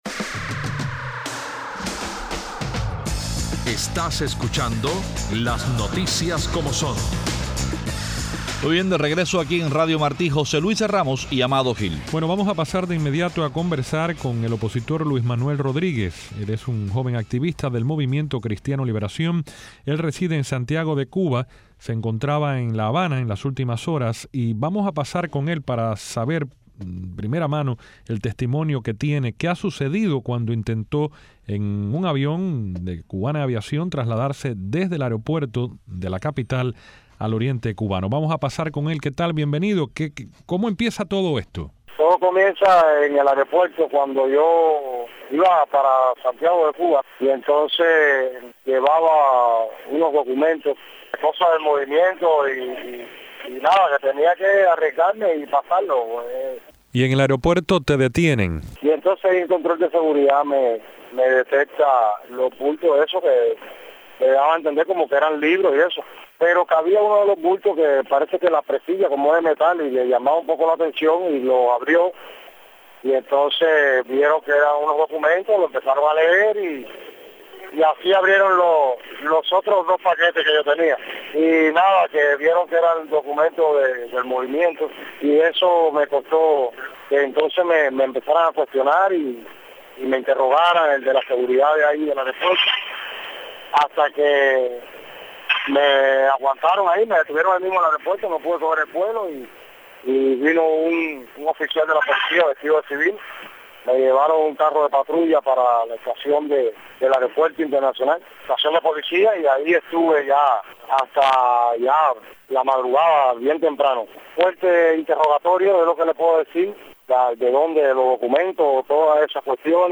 Nuestro invitado es el coordinador nacional de la UNPACU, José Daniel Ferrer García.